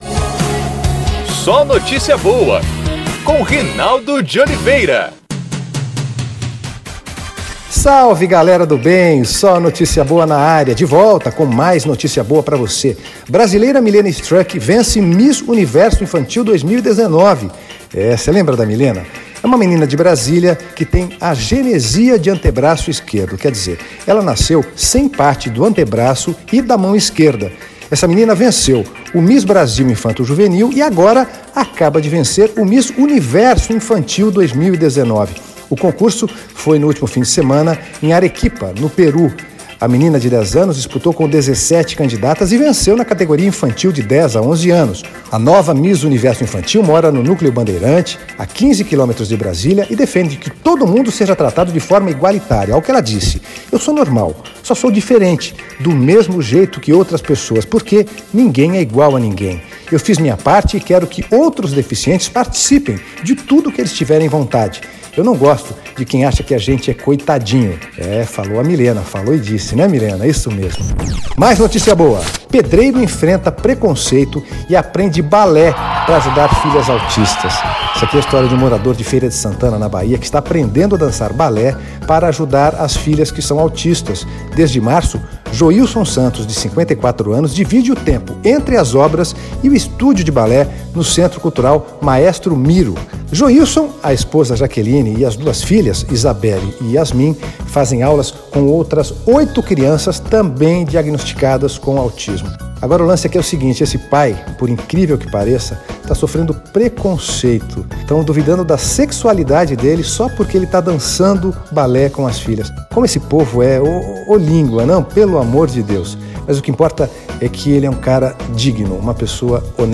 O Podcast SNB também é exibido em pílulas na programação da Rádio Federal.